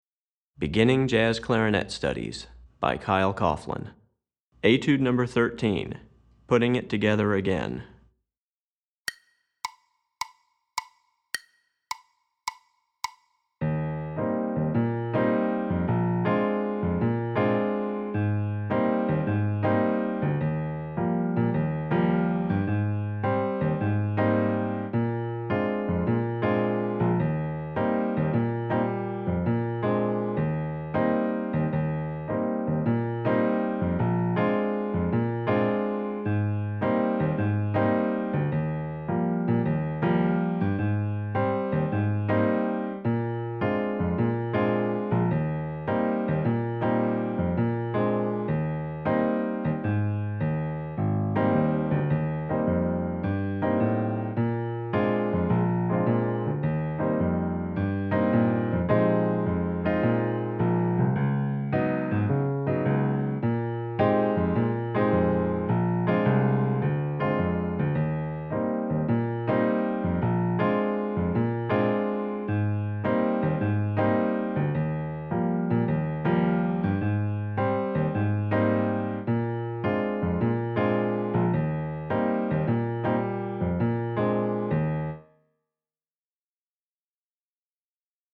Piano Accompaniment